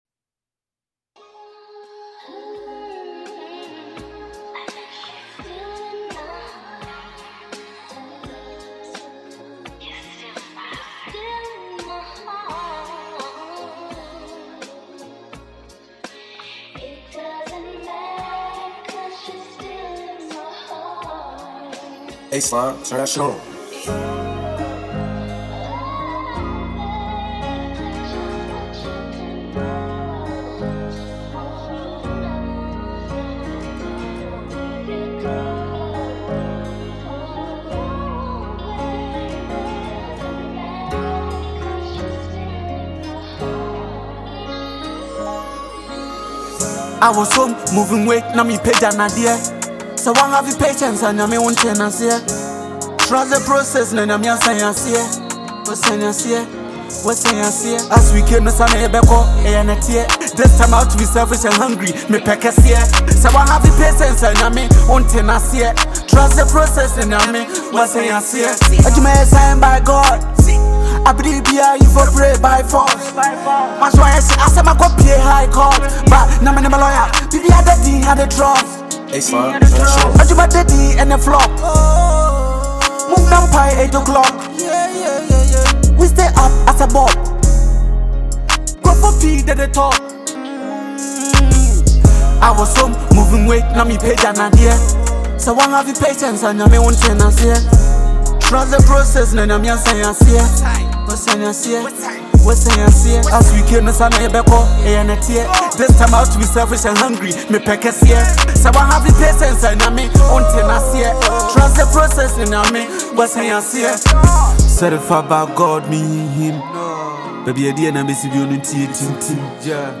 a bold and inspirational Ghanaian hip-hop record
Genre: Hip-Hop / Inspirational